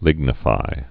(lĭgnə-fī)